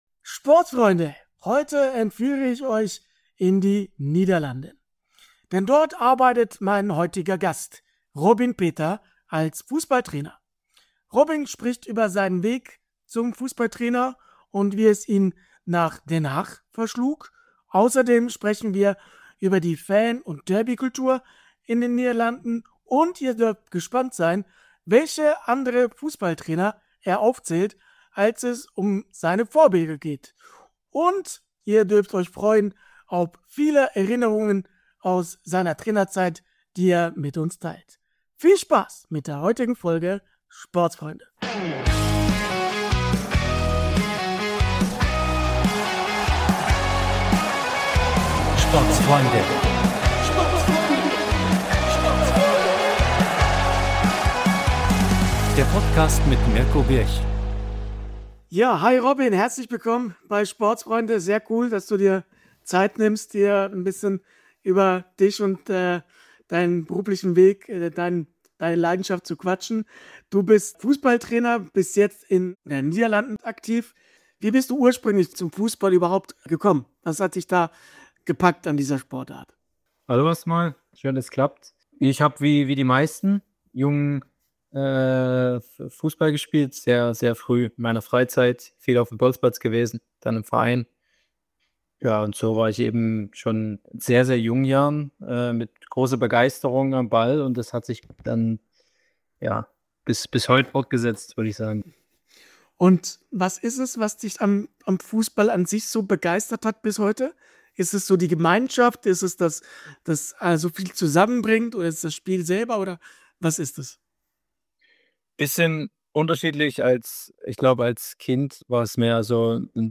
In ungezwungener Atmosphäre und immer auf Augenhöhe spreche ich in offenen, ehrlichen und authentischen Gesprächen mit ehemaligen und aktiven Sportlerinnen und Sportlern, Managern, Funktionären, Trainern, Schiedsrichtern, Journalisten und vielen weiteren Akteurinnen und Akteuren des Sports.
Wie im Sport selbst geht es dabei manchmal emotional zu, manchmal werden leise, manchmal laute Töne angeschlagen.